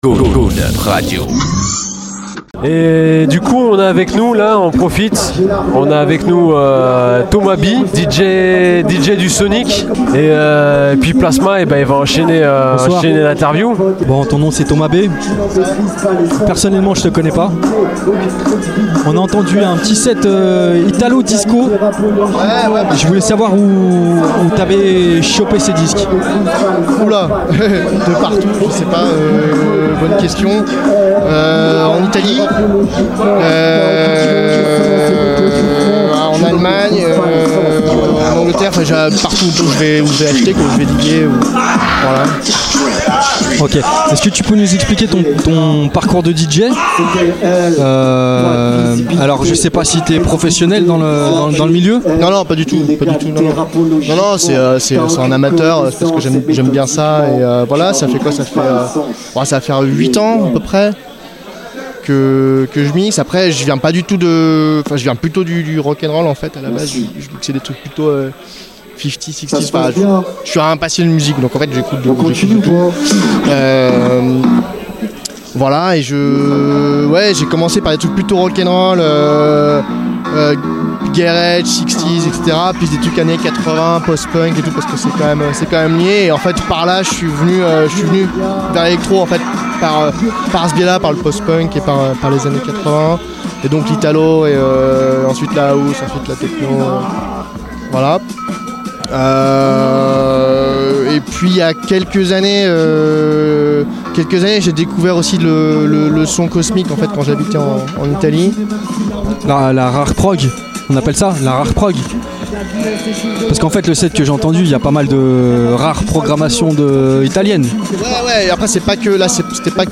WRECKS ON WEEKEND REPORT – INTERVIEW